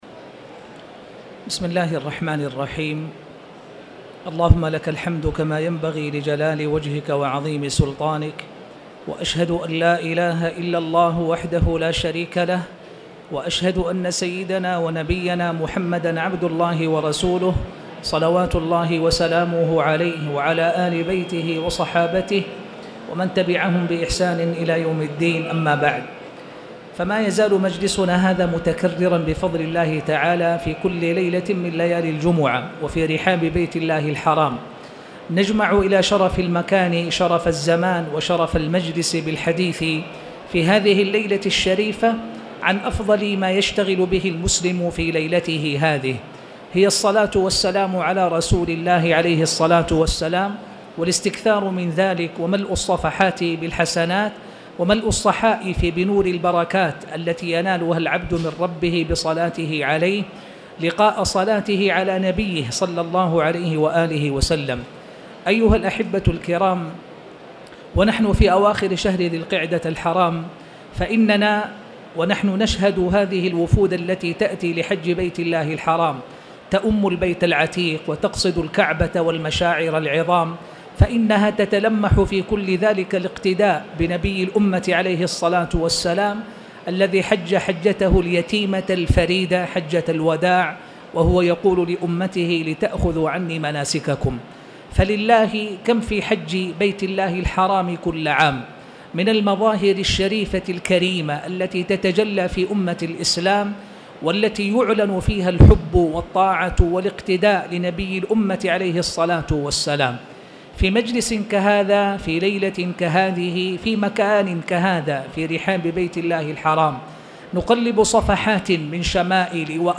تاريخ النشر ٢٢ ذو القعدة ١٤٣٧ هـ المكان: المسجد الحرام الشيخ